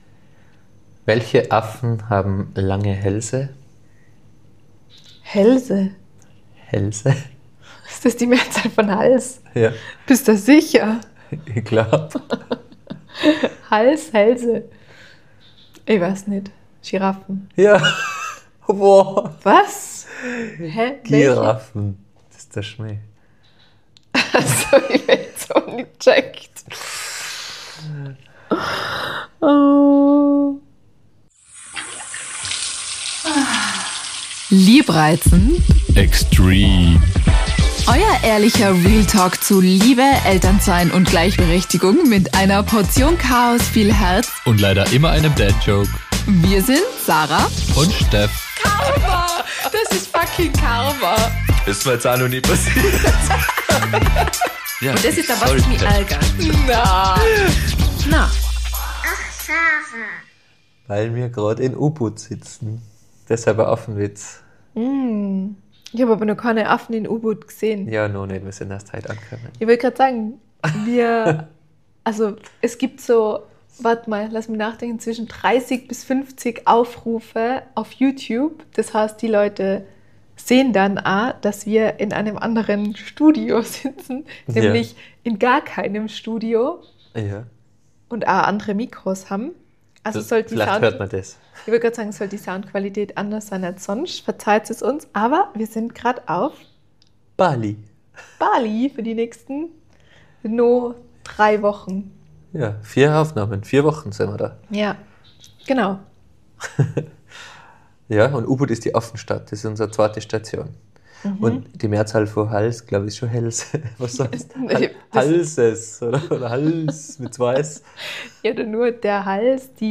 Die neue Podcast-Folge kommt mehr oder weniger live und mit ein klein wenig Zeitverschiebung direkt aus Bali